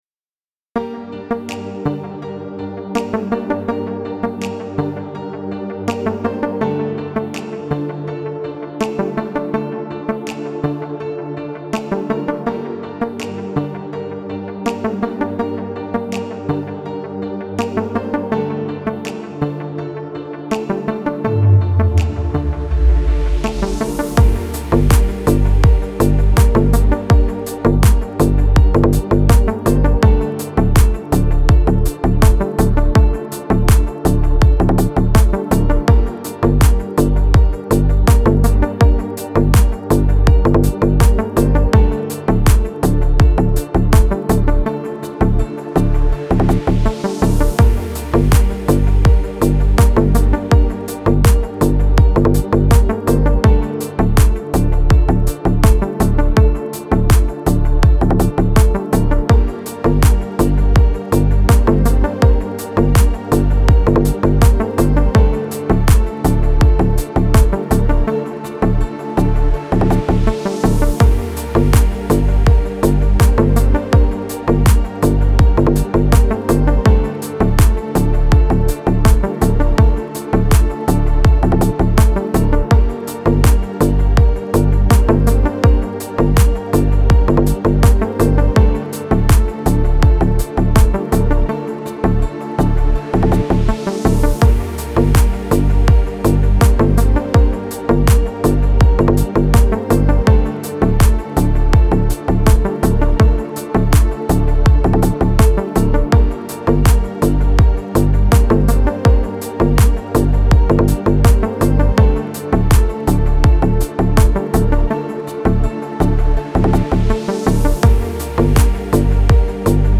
موسیقی بی کلام دیپ هاوس موسیقی بی کلام ریتمیک آرام